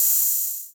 soft-hitwhistle.wav